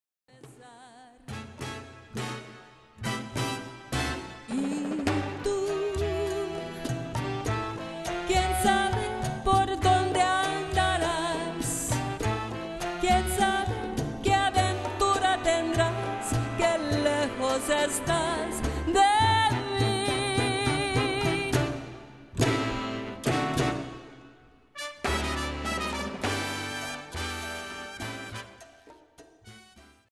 Category: little big band
Style: bolero
Solos: vibe or vocals